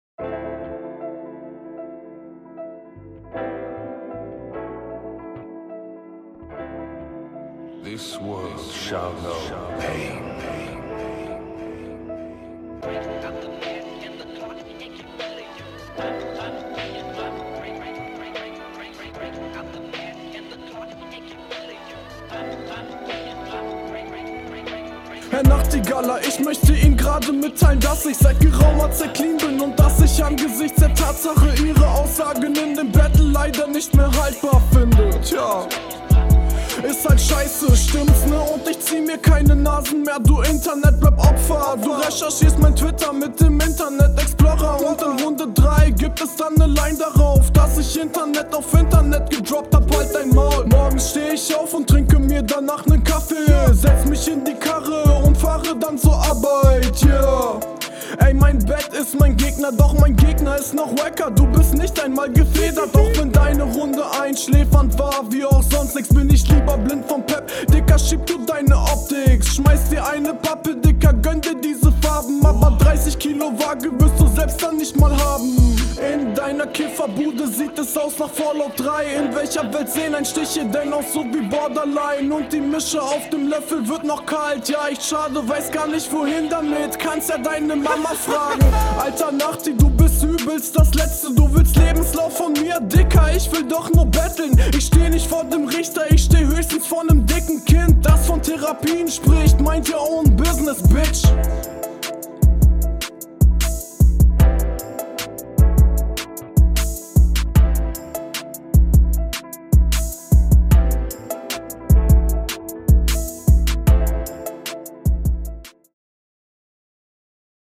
Stimme fast schon zu fett.